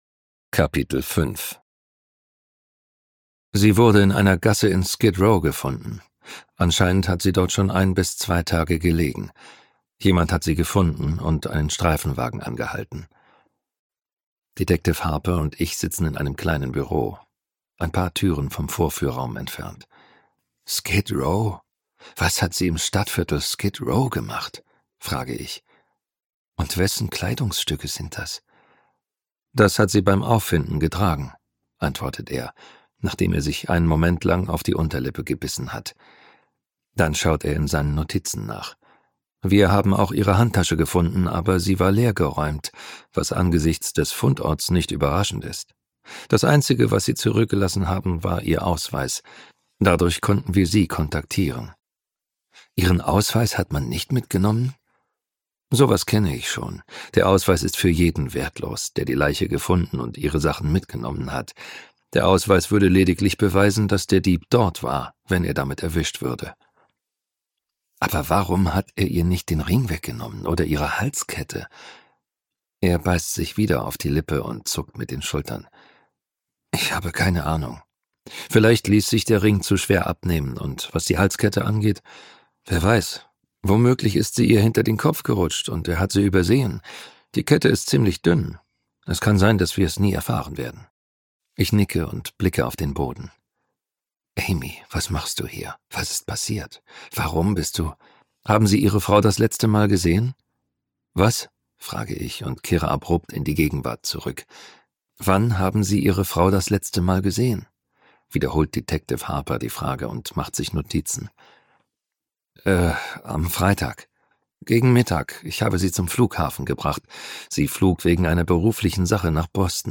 dunkel, sonor, souverän, markant, sehr variabel
Mittel plus (35-65)
Audiobook (Hörbuch)